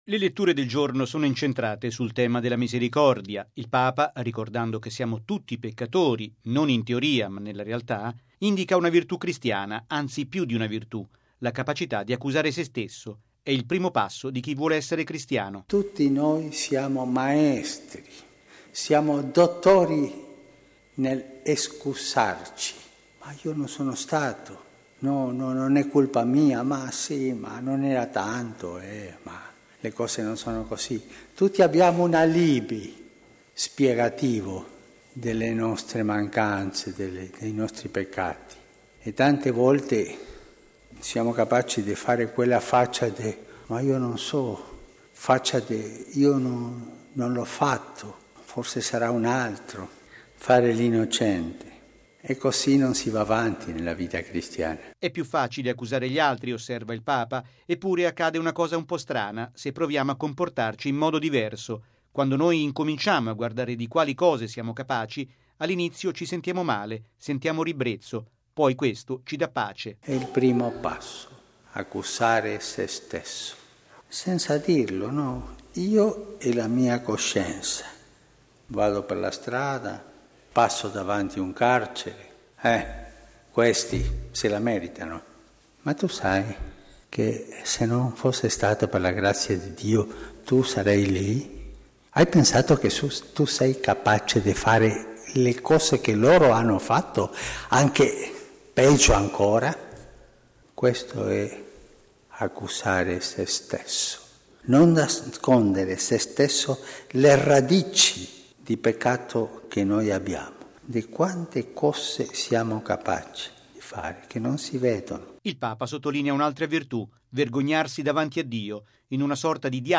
E’ facile giudicare gli altri, ma si va avanti nel cammino cristiano solo se si ha la sapienza di accusare se stessi: è quanto ha detto il Papa riprendendo, dopo gli esercizi spirituali, a celebrare la Messa a Santa Marta con i gruppi.